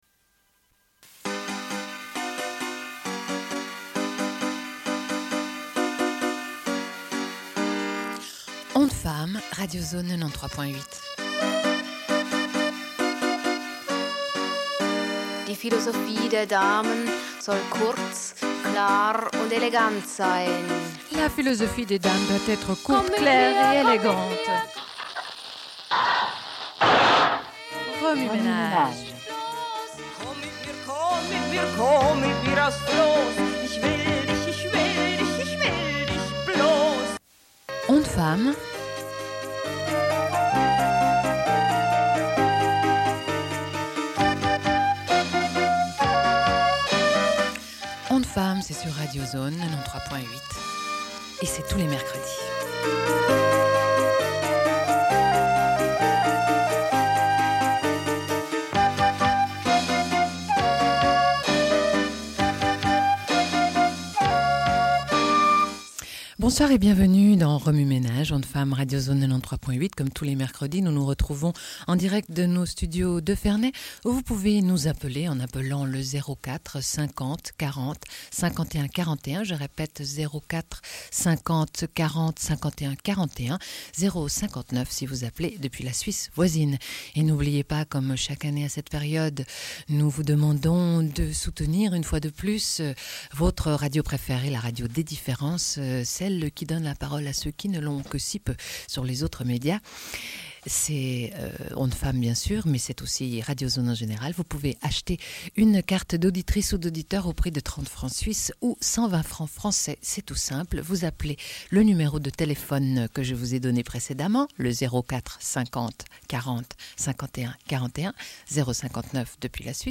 Sommaire de l'émission : au sujet de Clarice Lispector, écrivaine brésilienne. Lecture d'extraits de ses nouvelles Amour, Miss Algrane et Bruit de Pas.
Une cassette audio, face A